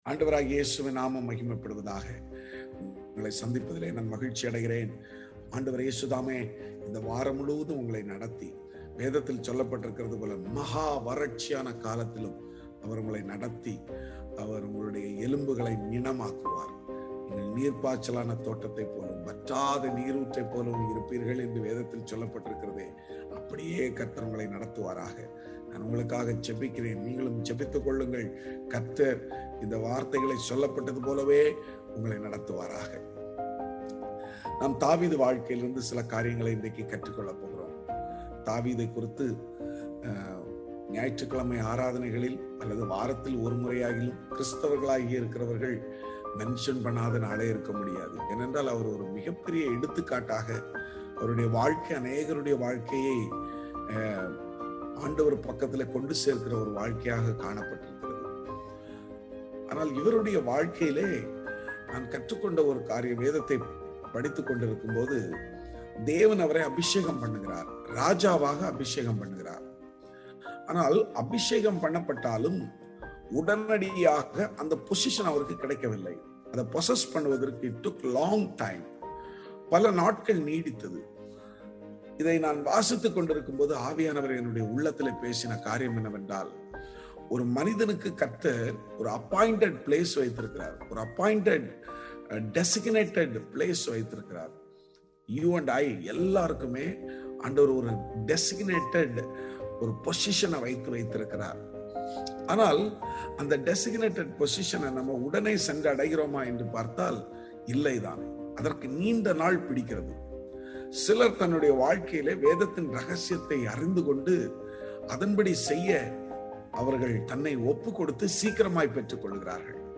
Morning Devotion